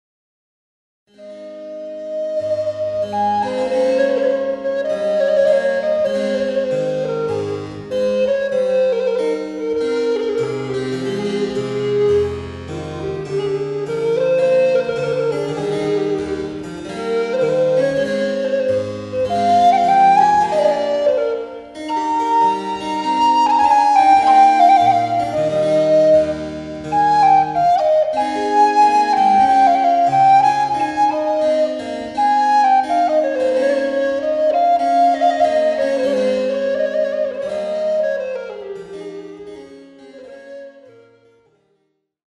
Classical, Early music